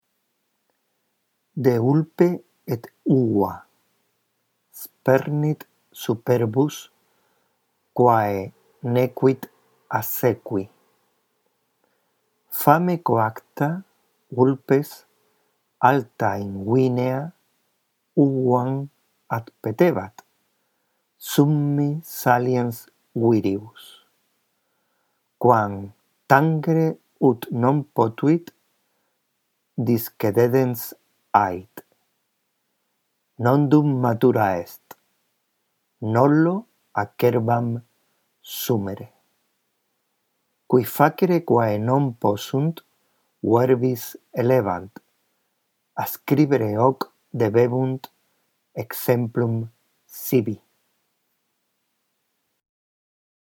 La audición de esta grabación puede ayudarte a mejorar tu lectura del latín: